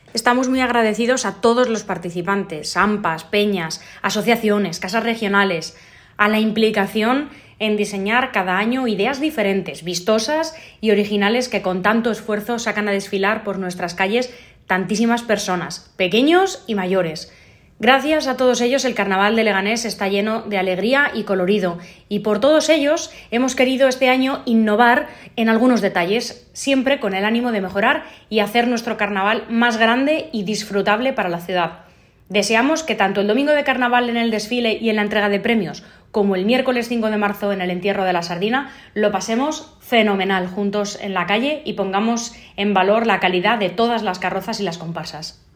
CONCEJALA-FESTEJOS-CARNAVAL-2025.mp3